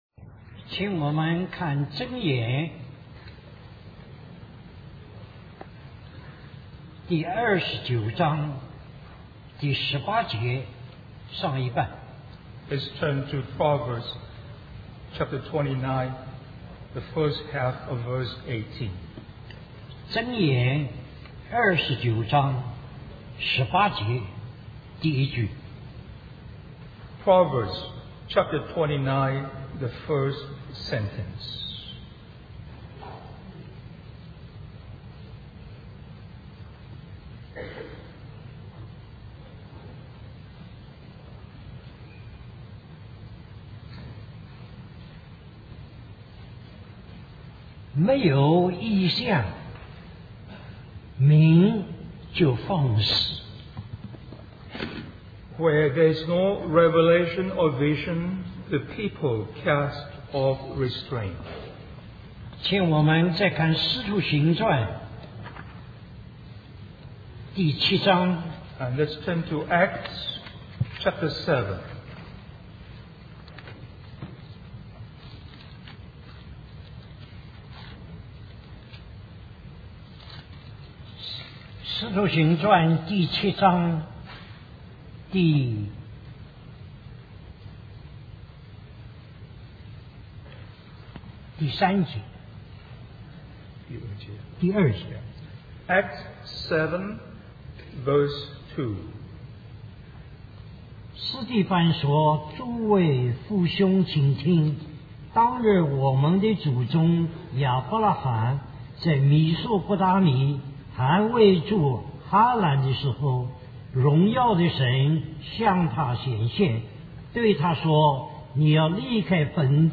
A collection of Christ focused messages published by the Christian Testimony Ministry in Richmond, VA.
Special Conference For Service, Taipei, Taiwan